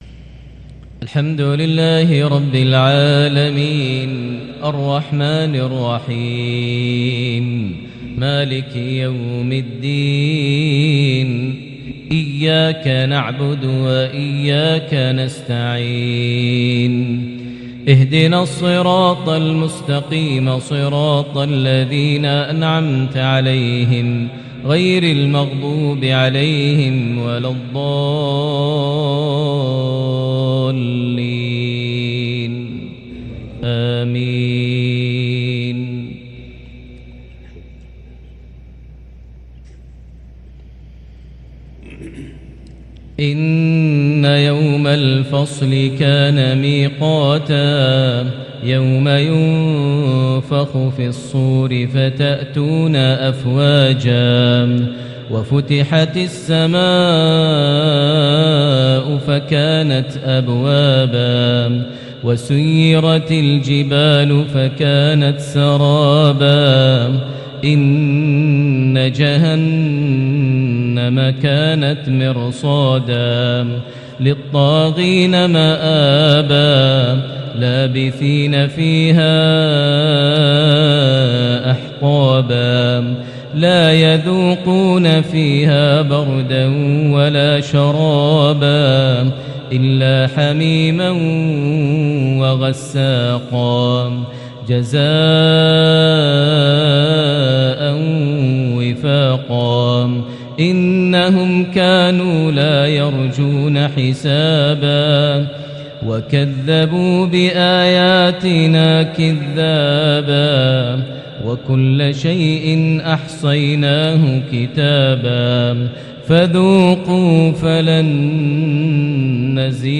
maghrib 3-6-2022 prayer from Surah An-Naba > 1443 H > Prayers - Maher Almuaiqly Recitations